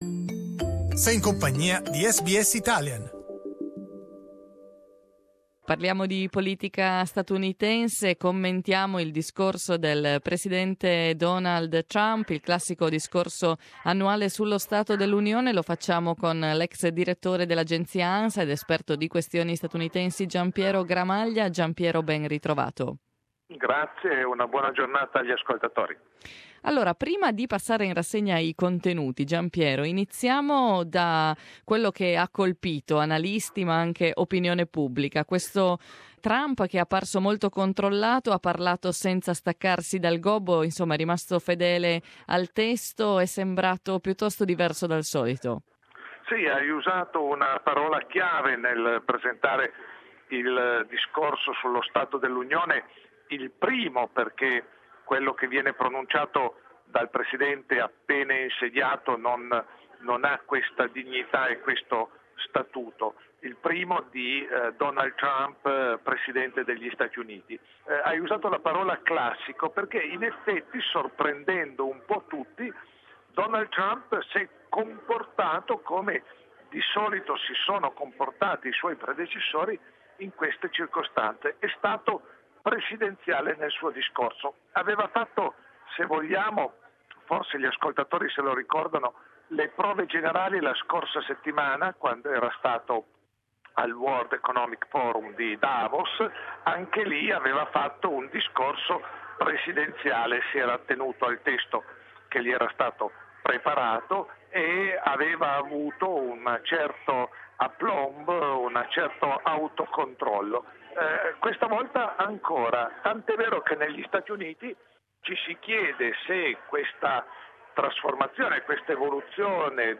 We talk about it with Italian journalist